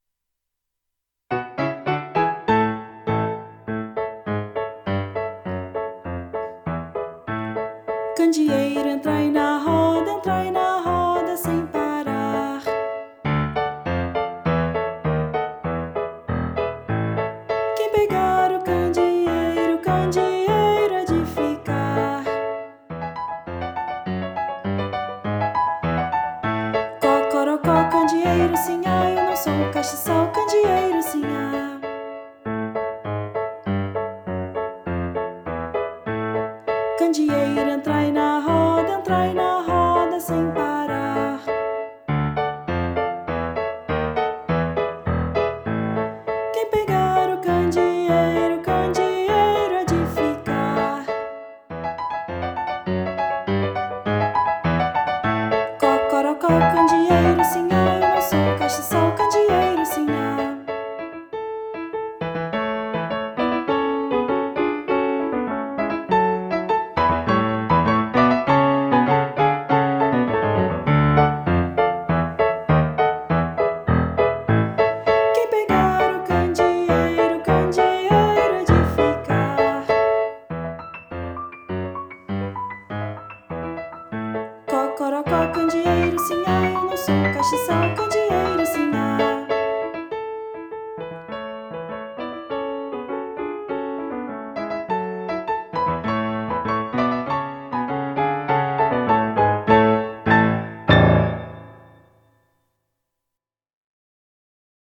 Voz Guia 2